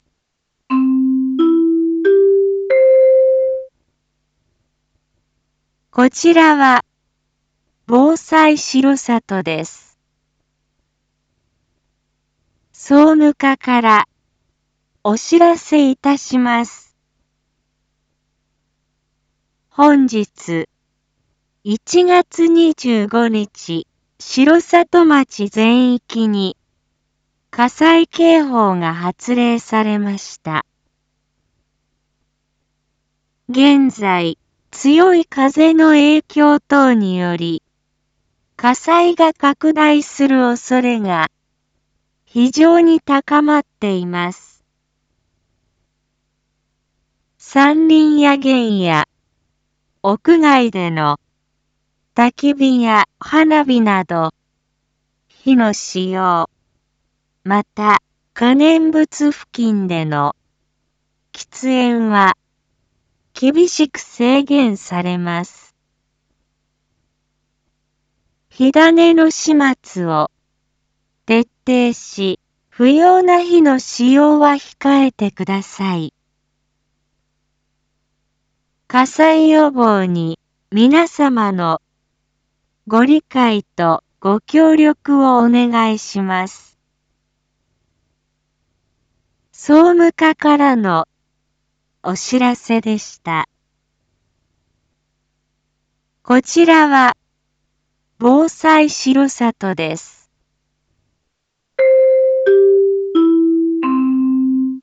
一般放送情報
Back Home 一般放送情報 音声放送 再生 一般放送情報 登録日時：2026-01-25 15:11:46 タイトル：火の取り扱いにご注意ください！（火災警報発令中） インフォメーション：令和8年1月25日15時00分現在、空気が乾燥し、火災が発生しやすい状況のため、城里町の全域に火の使用を制限する火災警報が発令されました。